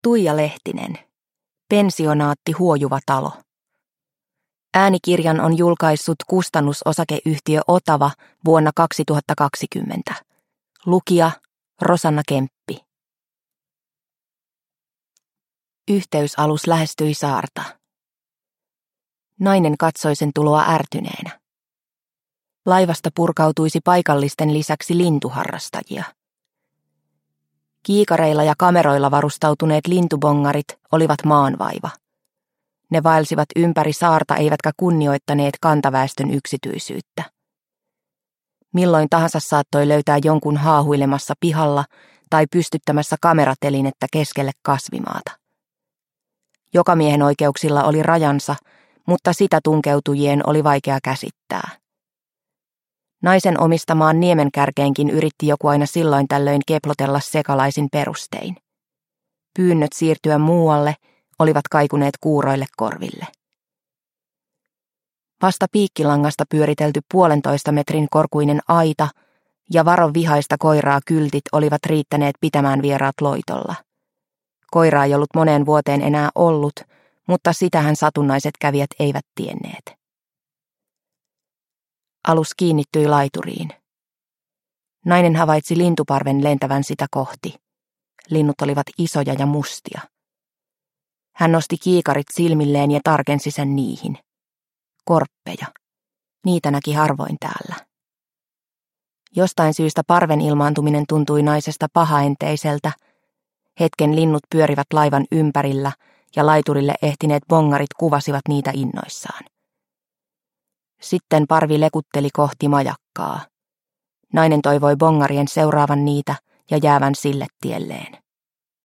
Pensionaatti Huojuva talo – Ljudbok – Laddas ner